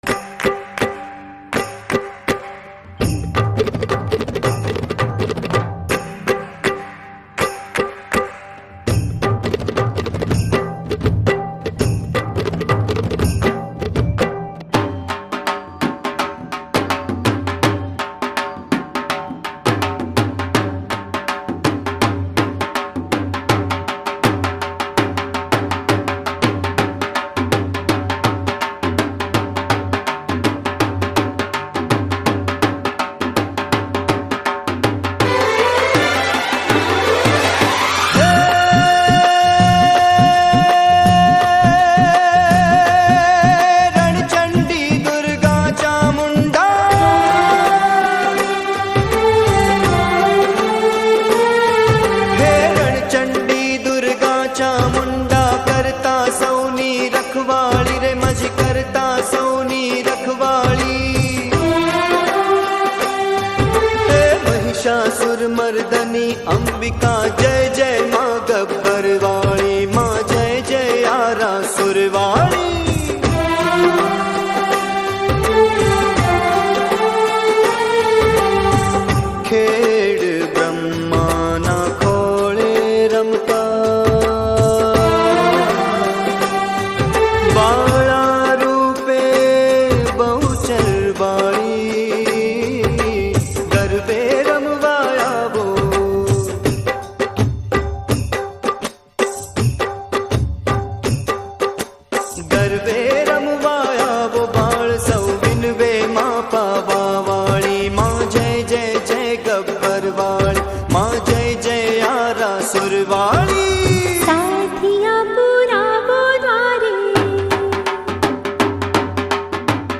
Gujarati Garba Navratri Special Garba